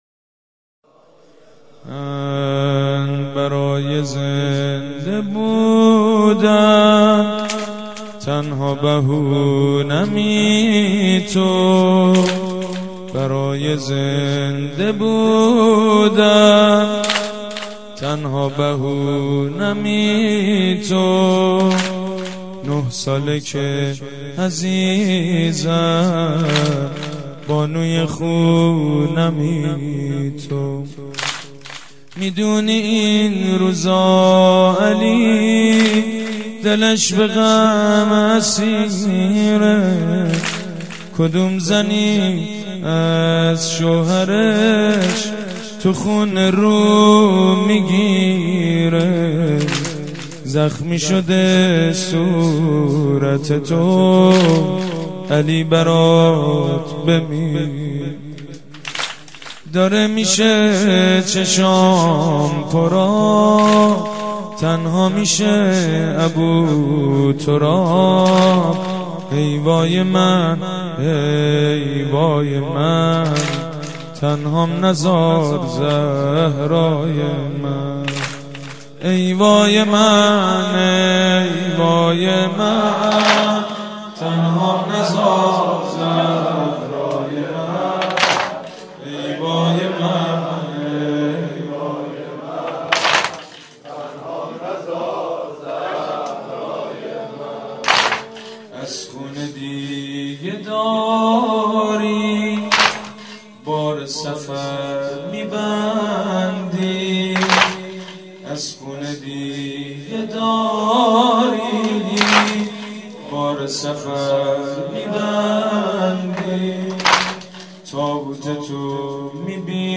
دو دمه